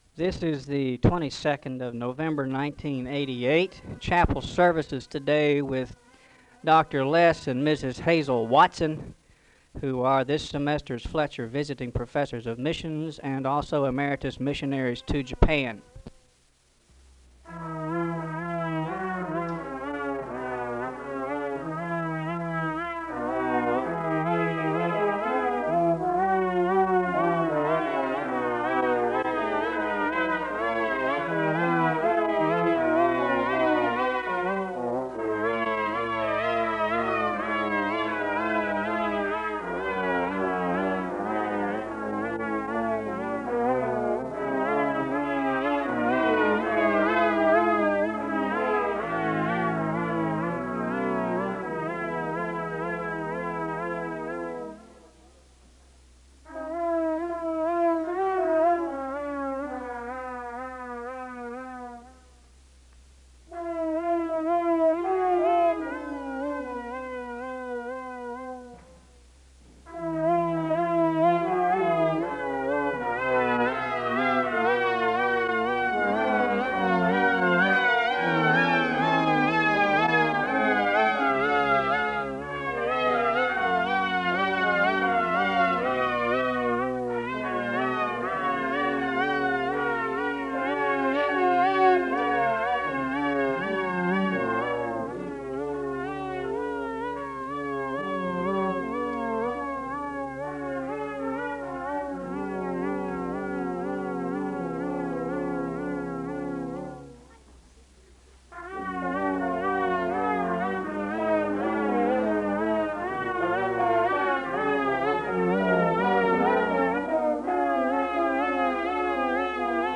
A hymn is played (0:17-2:31).
Another hymn is played (cut) (5:10-5:13). Scripture reading for the day comes from Acts 16:8-10 (5:14-5:52).
The choir sings an anthem (11:36-17:28).
A word of prayer closes the service (43:08-43:42).